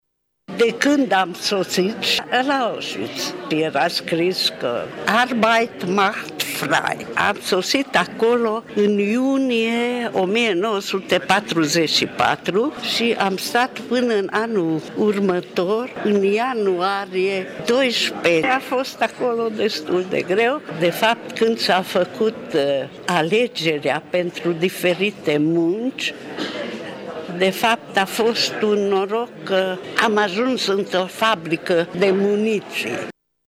Ceremonia a avut loc astăzi, la Sala de Oglinzi a Palatului Culturii din Tîrgu-Mureș.